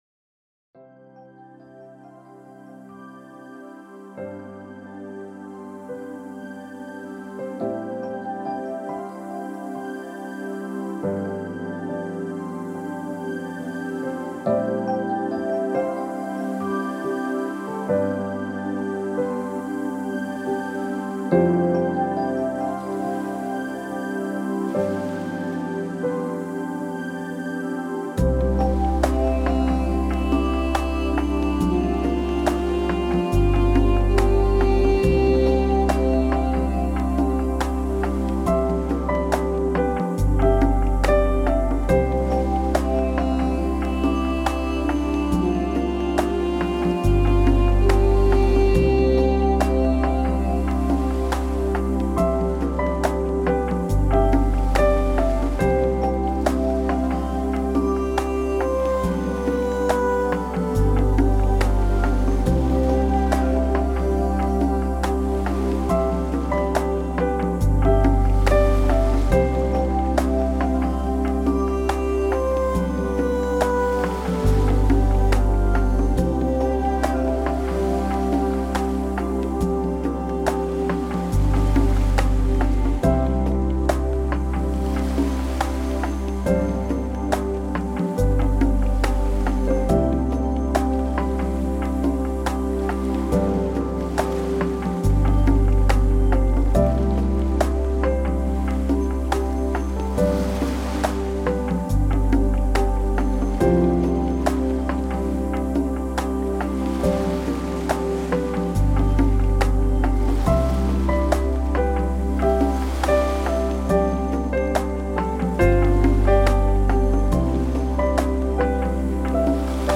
Musik